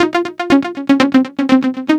TSNRG2 Lead 021.wav